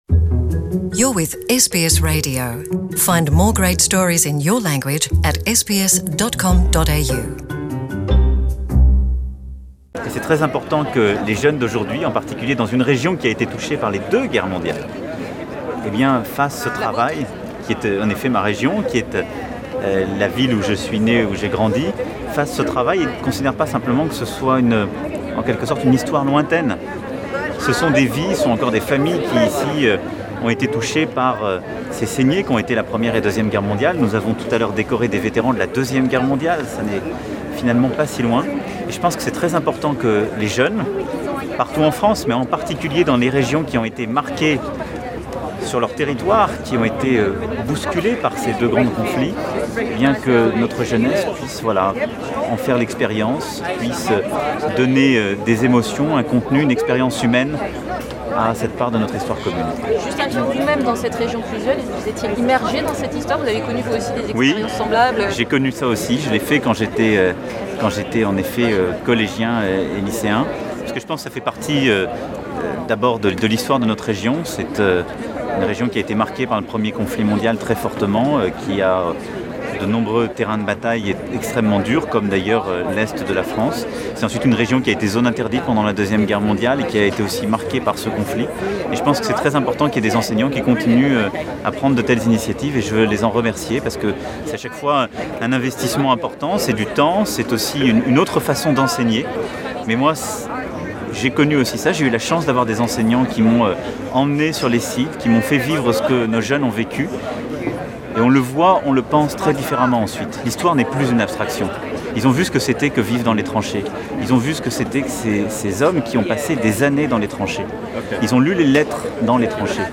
Emmanuel Macron sur Amiens lors la cérémonie donnée à l’ANZAC Memorial ce mercredi matin.